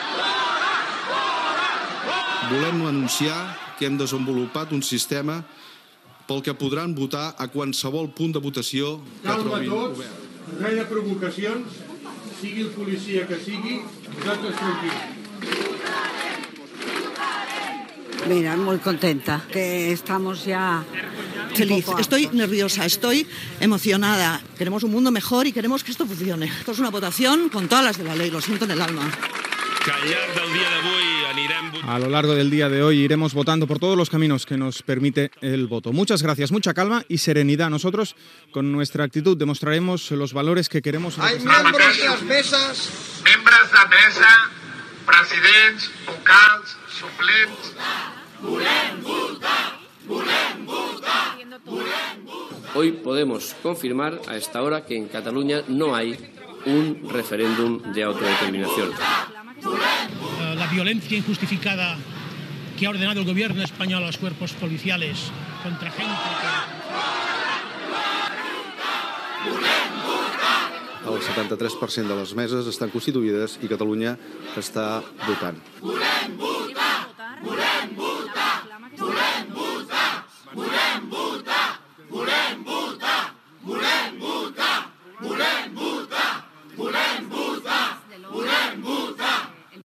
Resum de sons als col·egis electorals, el dia del Referèndum d'Autodeterminació de Catalunya de l'1 d'octubre
Informatiu